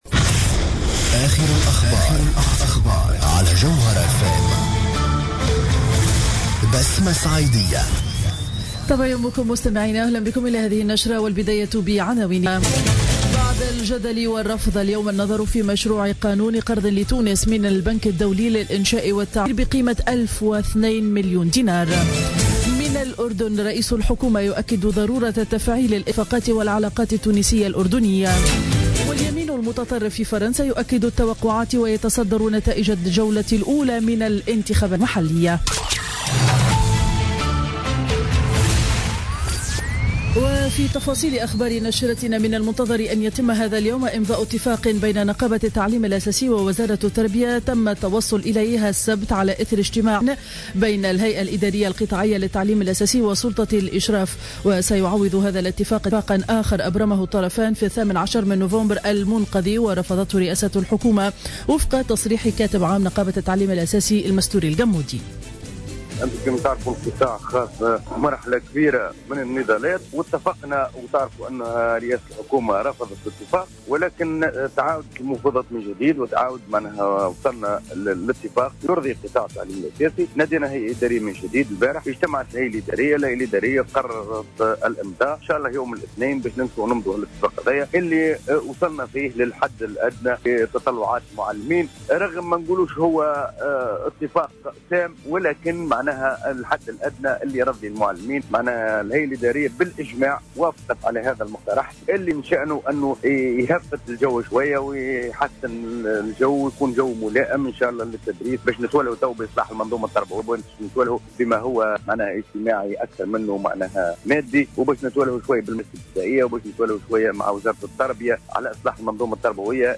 نشرة أخبار السابعة صباحا ليوم الاثنين 07 ديسمبر 2015